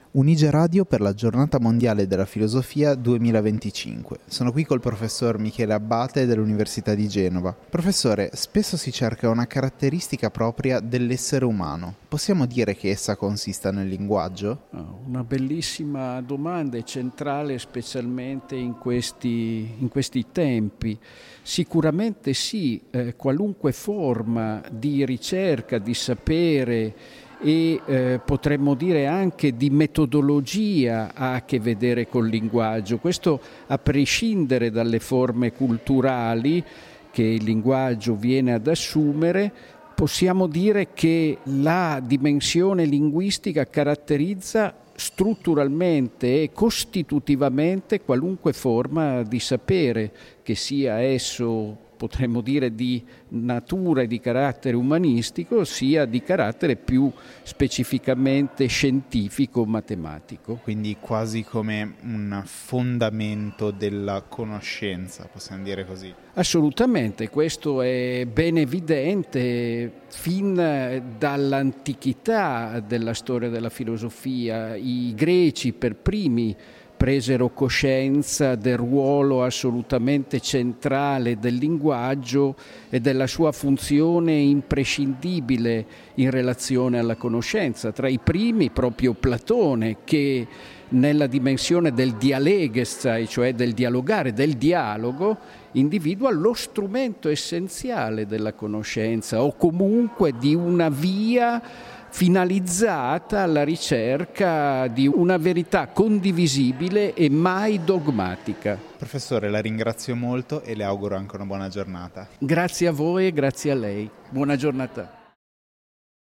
Un’intervista che riscopre il valore del dialogo nella ricerca della verità.